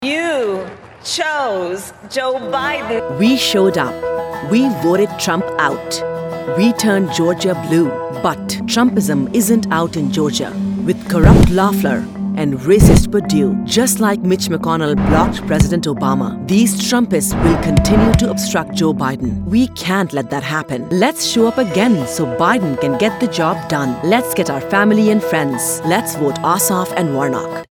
She can modulate her voice to be serious, cheeky, matter-of-fact, motivational, exhorting or whatever else the content demands.
A LITTLE SECRET - Did you know that using a TransAtlantic voice (not too American, not too British, but a bit of both) perks up the ears of the listener helping your message stand out from the crowd?